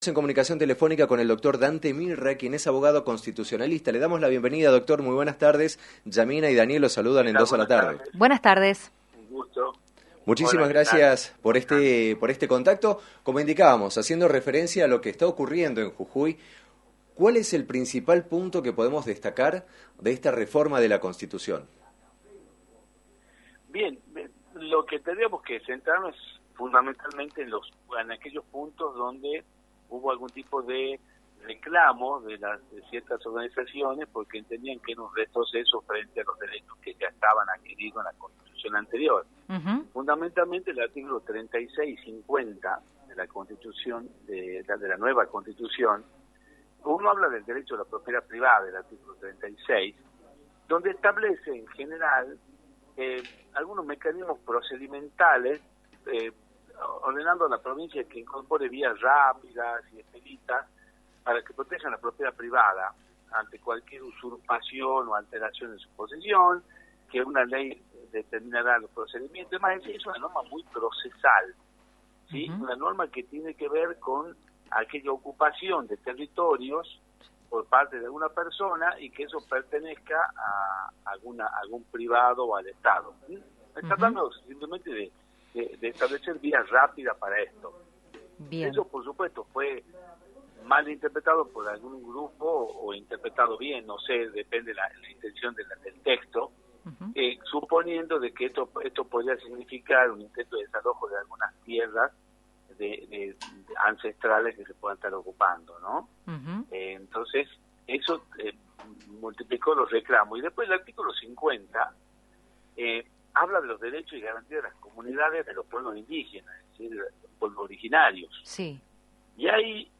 En diálogo con el programa «Dos a la Tarde» de Rock&Pop FM 106.9
abogado constitucionalista